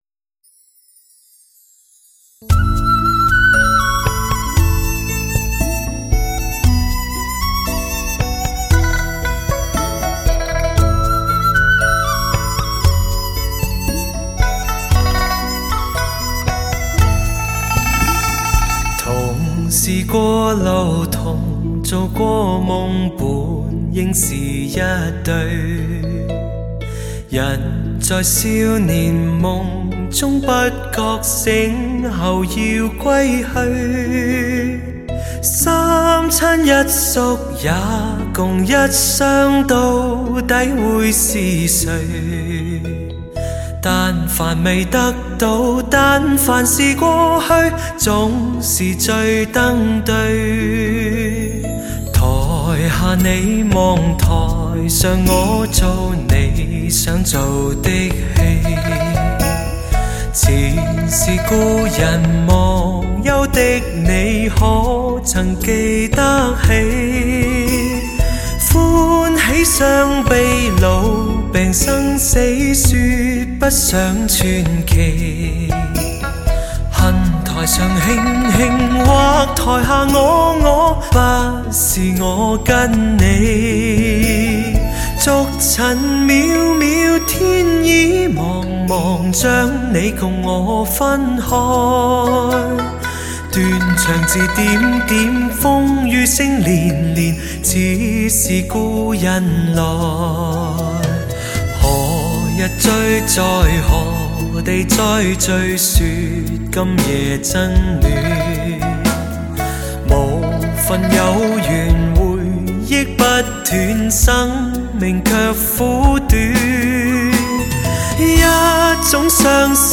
年度最佳情歌专辑
诠释男性挚情挚爱，情人怀抱般温暖嗓音，非凡深情折服你我心。